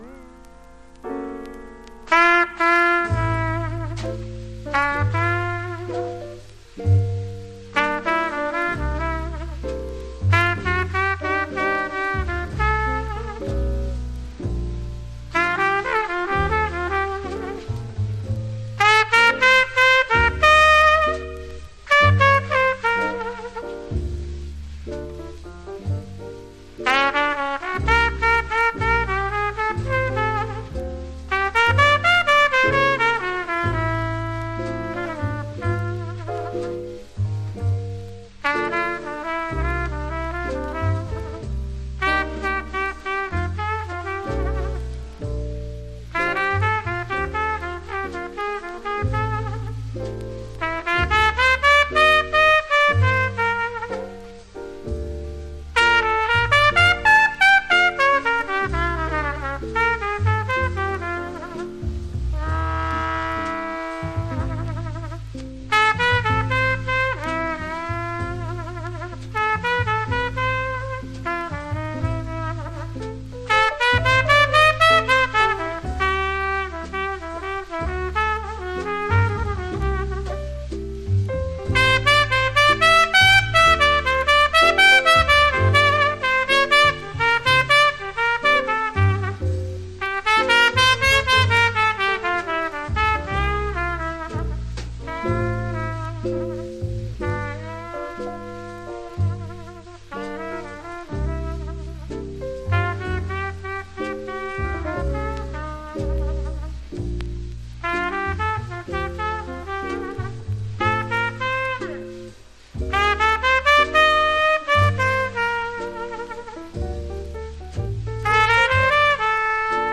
（MONO針で聴くとほとんどノイズでません）
Genre US JAZZ